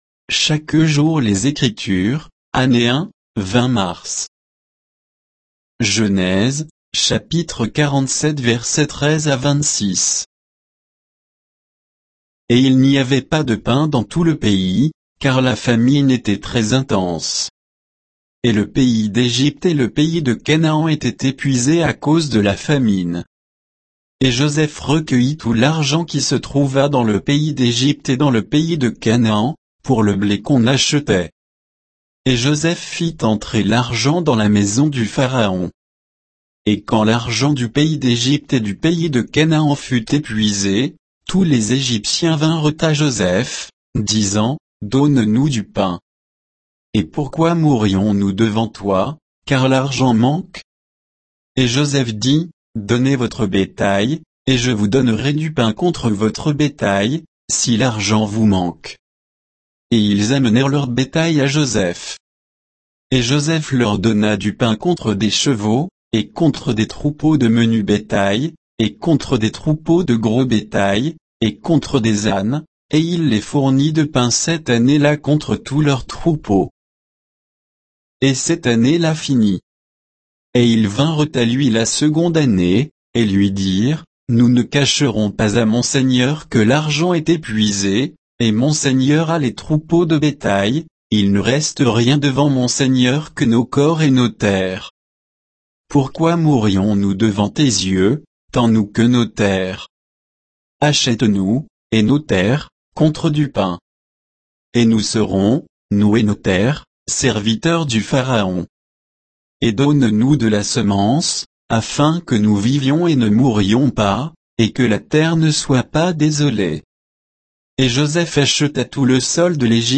Méditation quoditienne de Chaque jour les Écritures sur Genèse 47